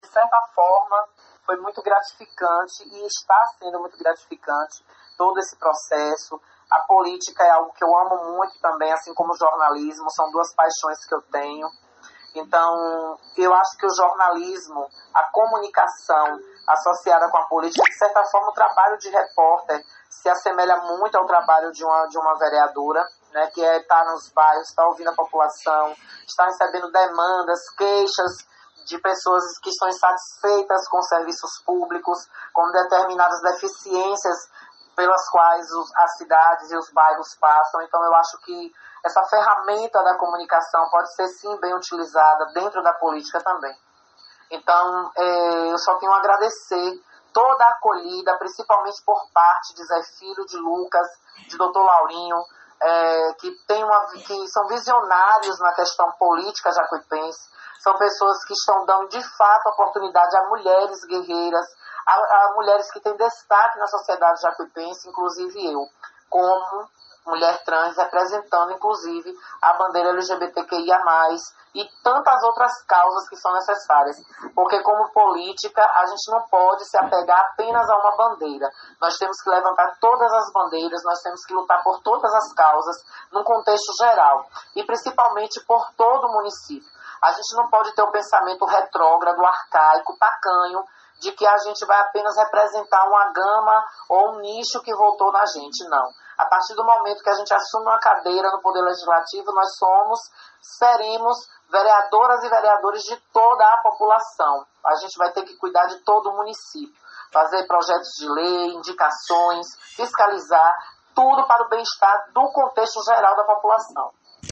Ela conversou com a reportagem do CN e destacamos alguns pontos de entrevista.